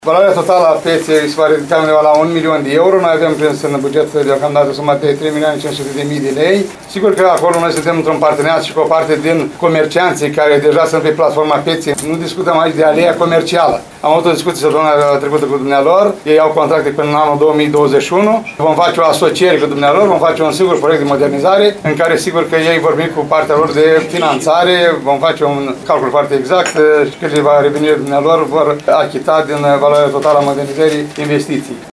Primarul ION LUNGU a declarat astăzi că zona Pieței Mici este una dintre cele mai aglomerate din municipiu și că se impun investiții pentru decongestionarea traficului.